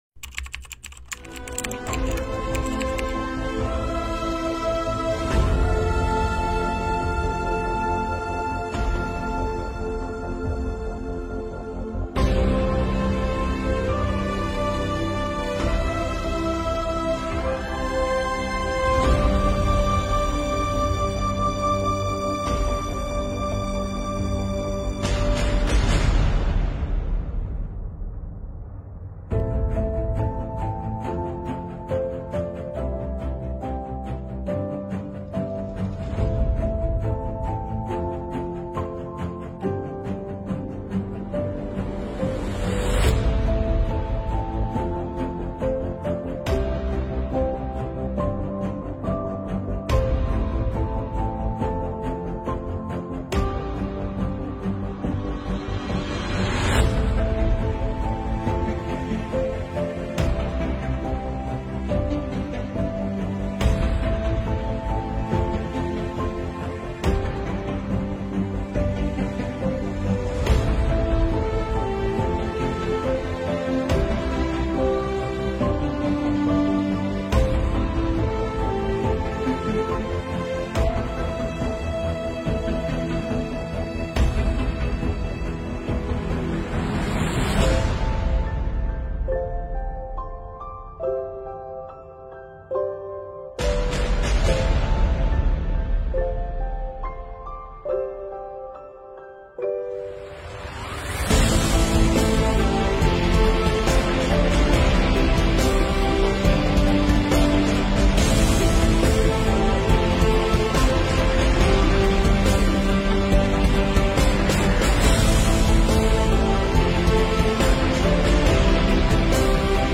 本次榜样讲堂邀请了全市税务系统驰援东兴、峒中、那良疫情防控工作人员代表，现场讲述战疫故事，展示榜样力量。